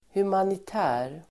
Ladda ner uttalet
Uttal: [humanit'ä:r]